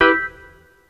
neptunespiano.wav